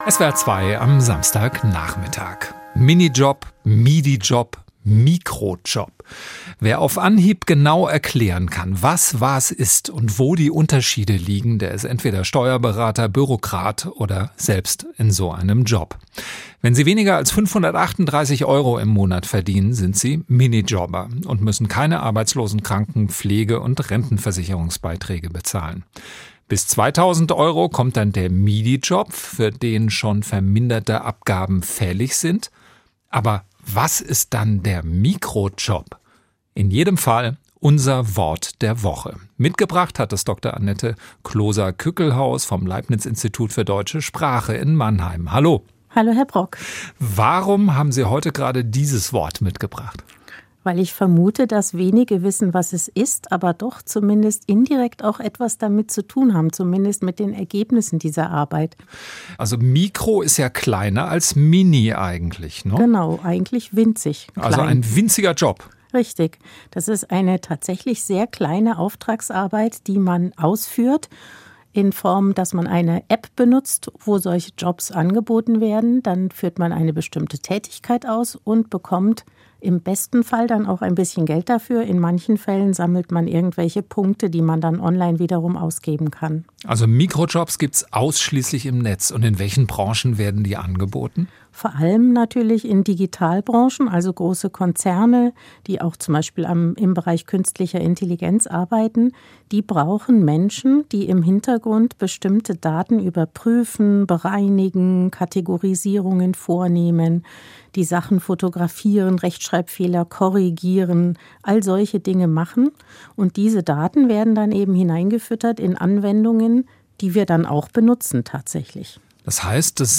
Das Gespräch führte